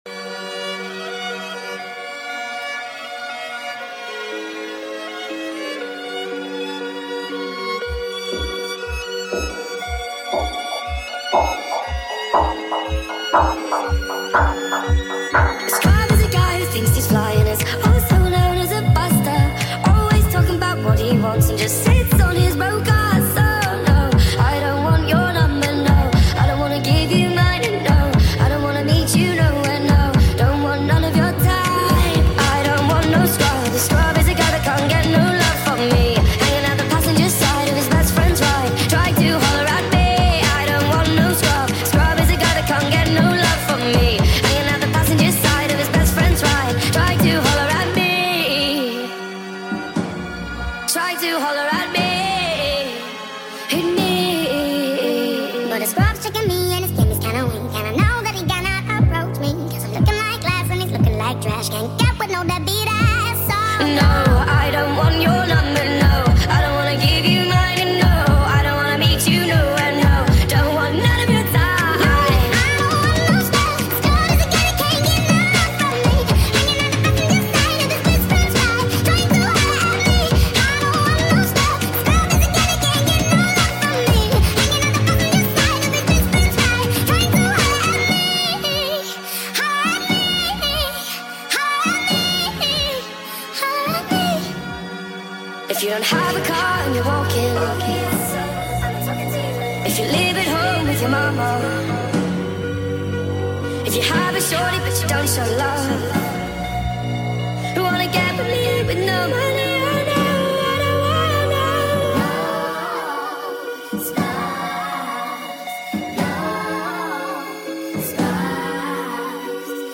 Full Speed Up Song